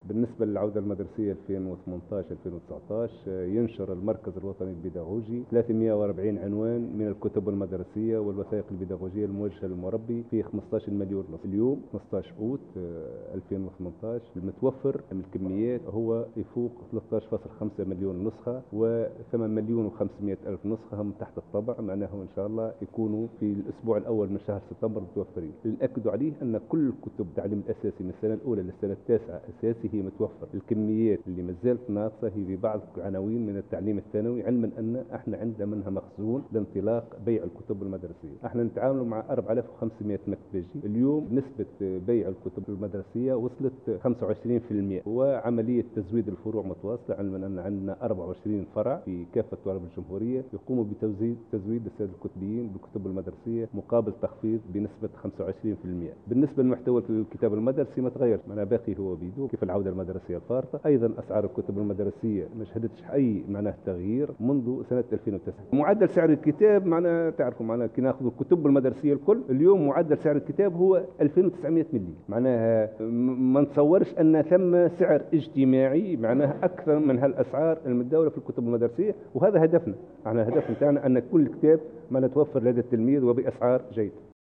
وتحدّث الرئيس العام للمركز بلڨاسم الأسود في تصريح لمراسلة "الجوهرة اف أم"، عن الكتب المُتوّفرة خلال السنة الدراسية المُقبلة. وجاء ذلك على هامش زيارة نظمتها وزارة التربية اليوْم لفائدة الصُحفيين للمركز الوطني البيداغوجي.